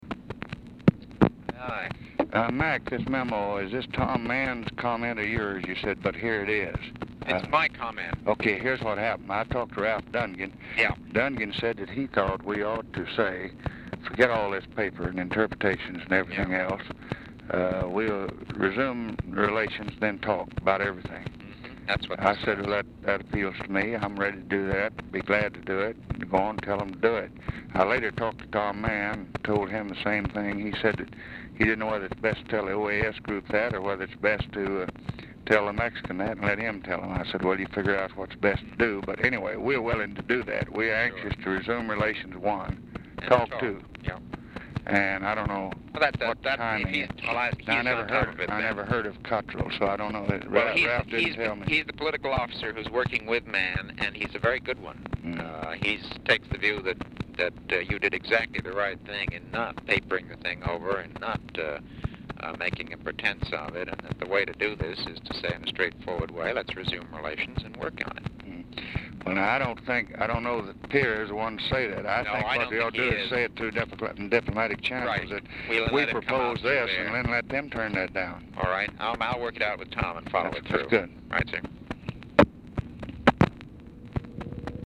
Telephone conversation # 2546, sound recording, LBJ and MCGEORGE BUNDY, 3/18/1964, 4:20PM?
Format Dictation belt
Oval Office or unknown location